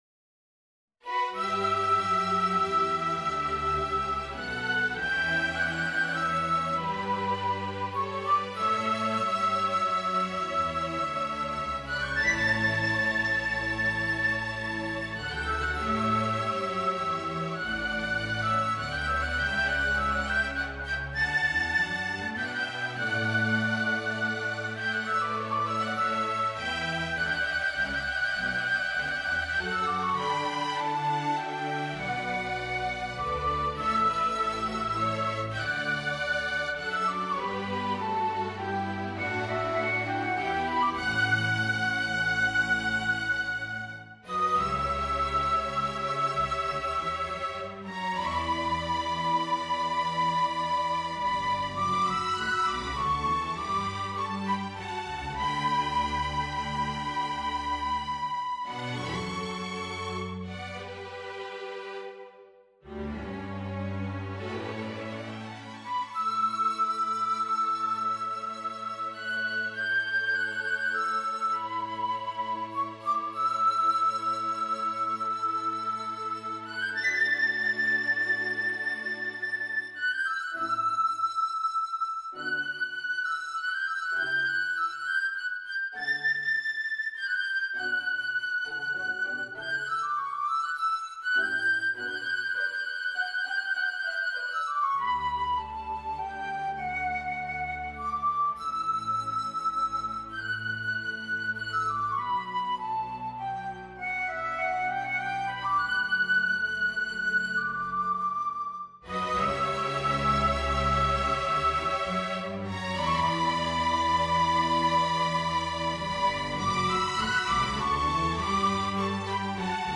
Mock-ups generated by NotePerformer can be heard below (to listen, click on the white arrowhead on the left of the bar).
A tonal and melodic suite for solo flute and strings made up of pieces inspired by Chamber Music, an early collection of poetry by James Joyce (1907).